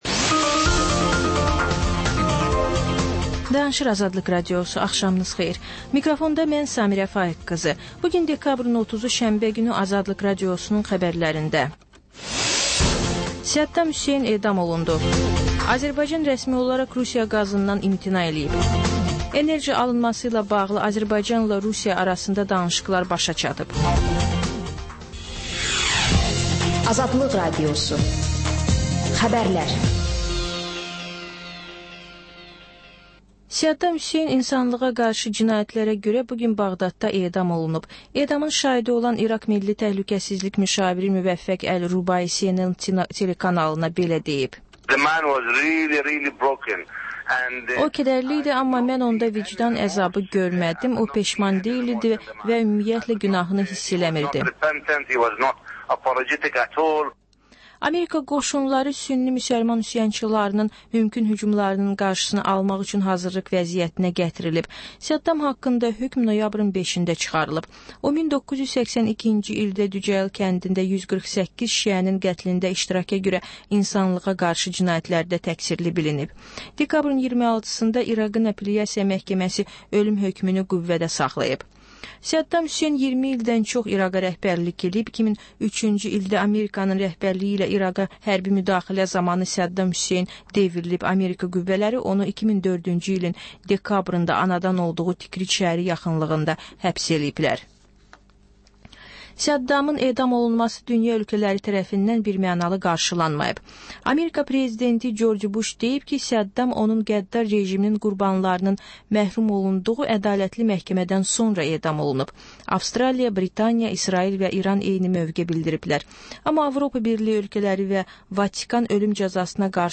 Xəbərlər, reportajlar, müsahibələr. Və: Qafqaz Qovşağı: Azərbaycan, Gürcüstan və Ermənistandan reportajlar.